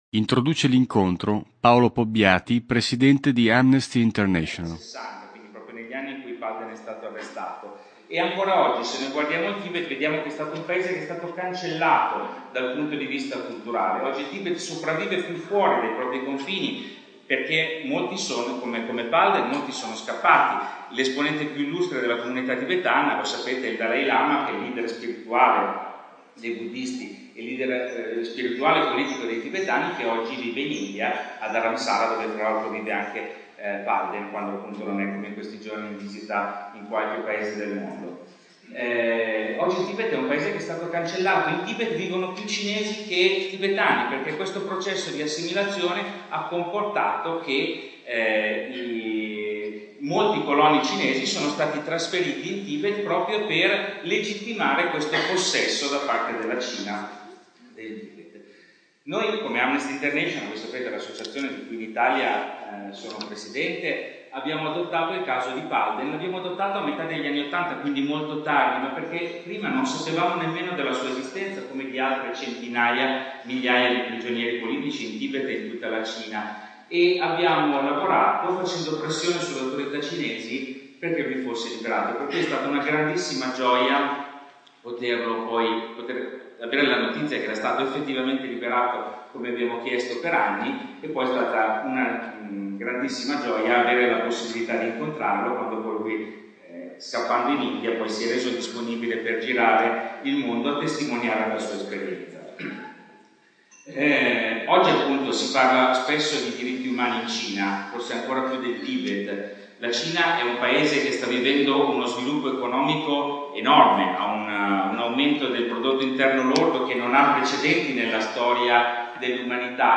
Download: Intervista al monaco tibetano Palden Gyatso
Palden Gyatso incontra gli studenti dell’ITSOS Steiner di Milano. Il monaco tibetano, rimasto in detenzione nelle carceri cinesi per trentadue anni solo per aver chiesto, in maniera pacifica e non violenta la liberazione del proprio paese, ha raccontato la propria drammatica esperienza di detenuto politico e il suo percorso da uomo libero dopo la sua scarcerazione e la sua fuga all’estero.